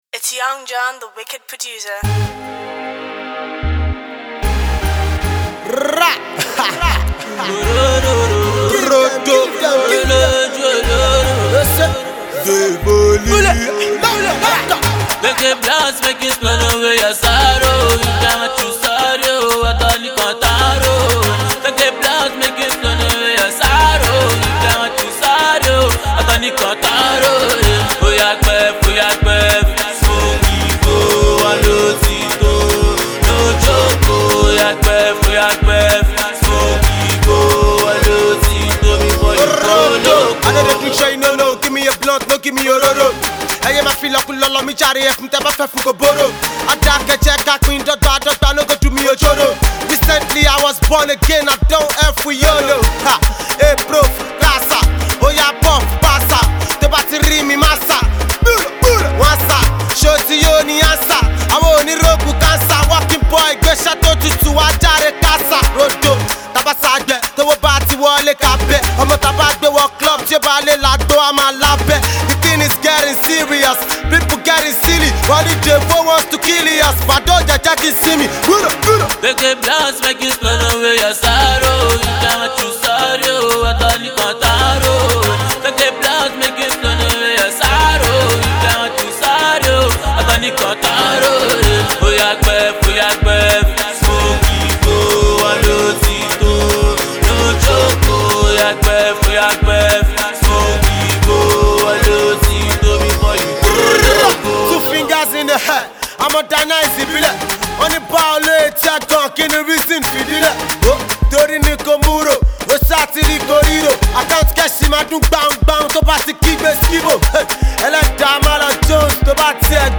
street banger
rapper